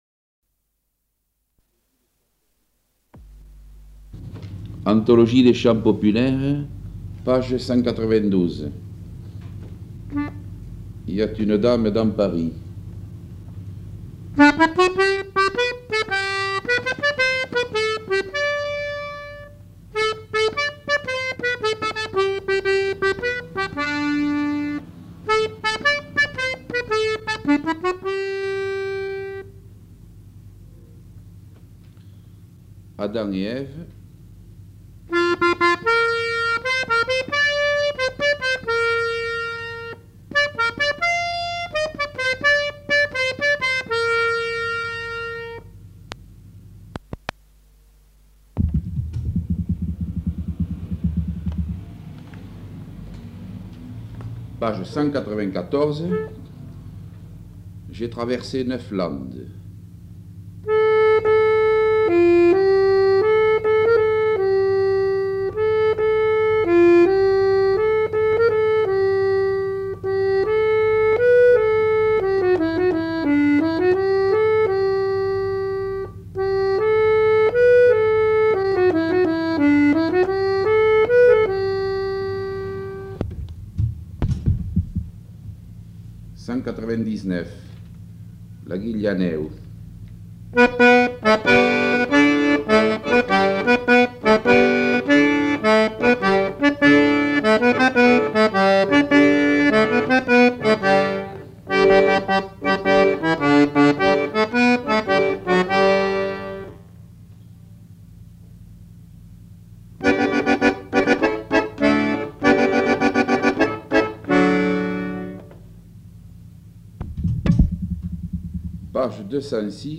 Répertoire d'airs gascons joué à l'accordéon chromatique
Nature du document : enquête
Type de son : mono
Qualité technique : bon
Il ne joue qu'une seule fois le thème de chaque air.
Langue : français ; occitan (gascon) Instrument de musique : accordéon chromatique Notes consultables : Les airs sont annoncés en français ou en occitan.